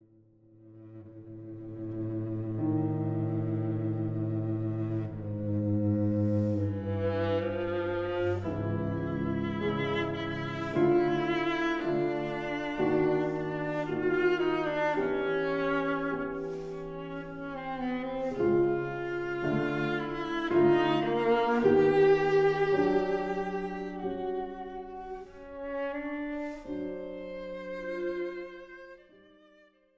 Slow, Mysterious - Broadly